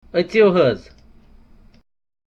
A conversation on visiting the mosque at Hazratbal, ten miles from downtown Srinagar.